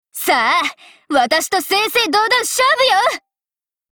Cv-20513_battlewarcry.mp3